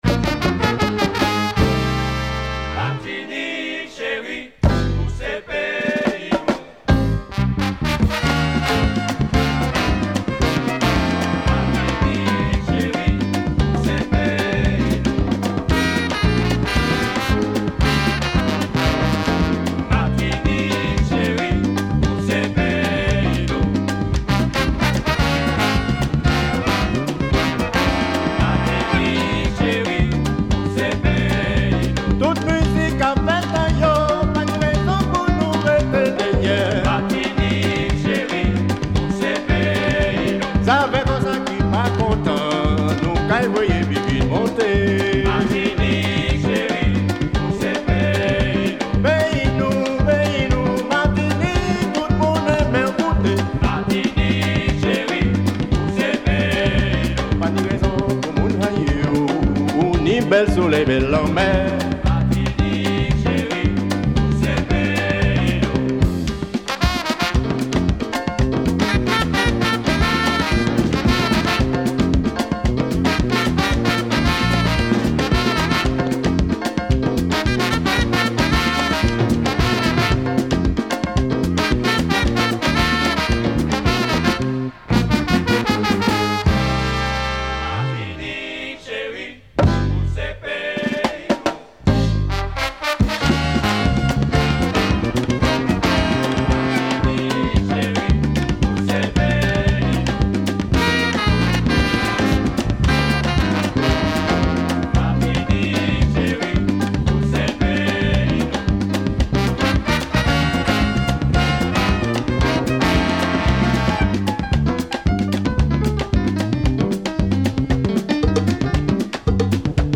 Creole latin jazz and gwo ka